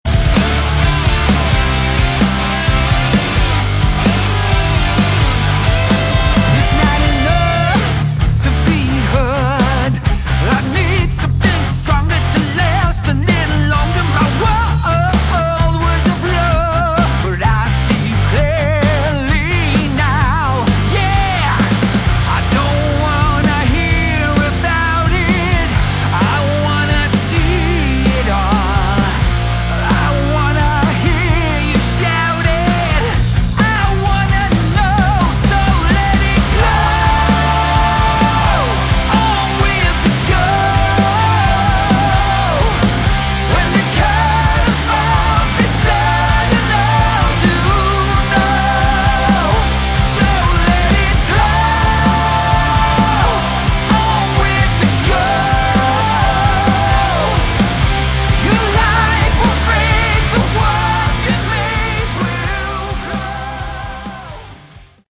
Category: Melodic Rock
vocals
bass
keyboards
guitars
drums